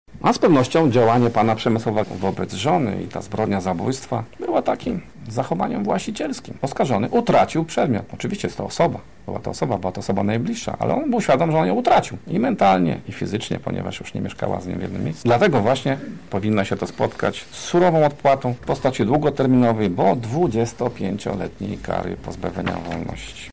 Wyrok  – mówi sędzia Piotr Skibiński z Sądu Okręgowego w Lublinie.